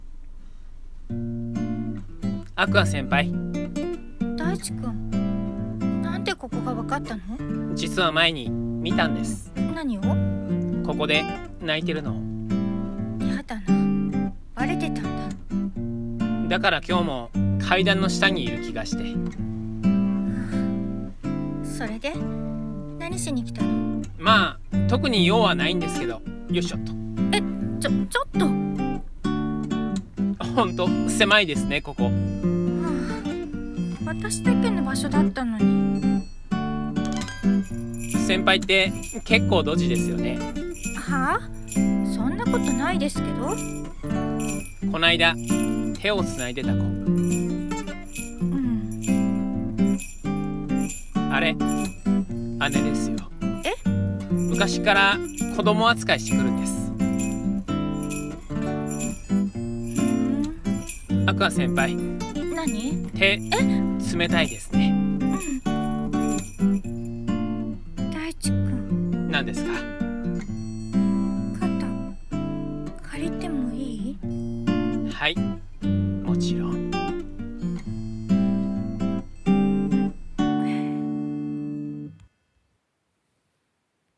【恋愛声劇】
【2人台本】